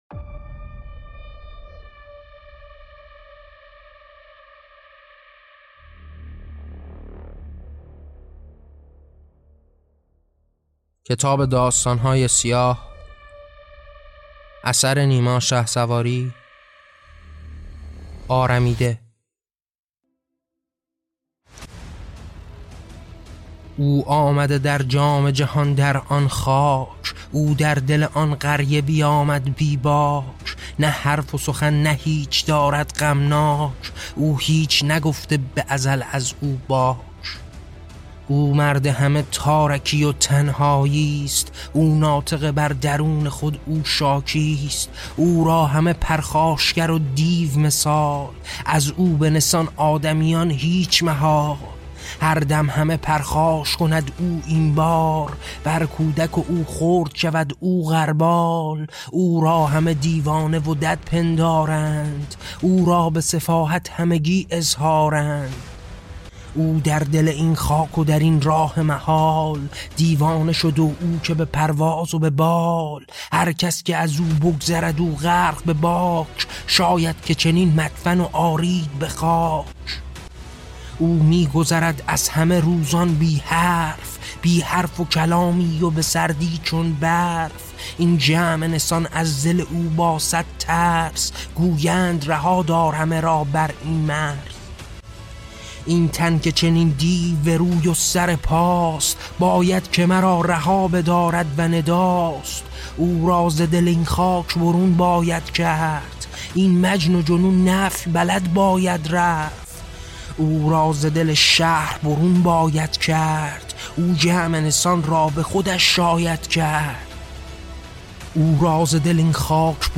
داستان صوتی آرمیده